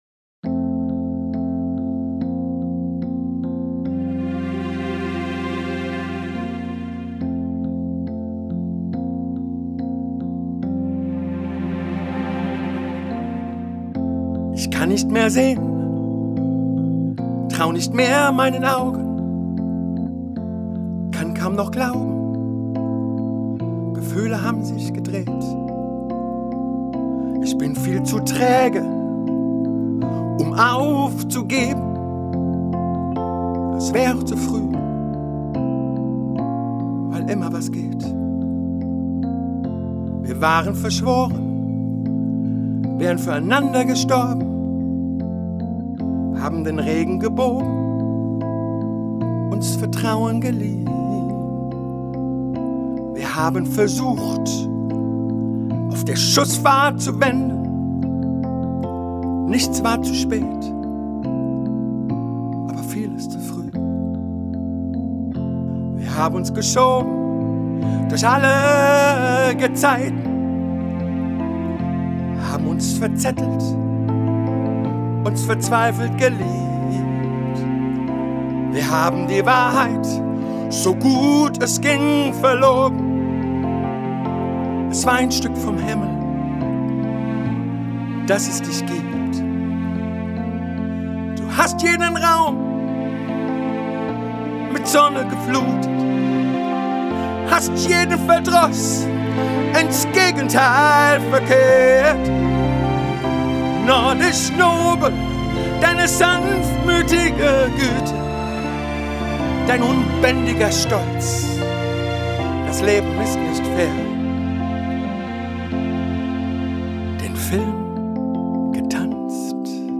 Ein kraftvolles Stück zu einer Beerdigung
freier Beerdigungssänger
weltliche Lieder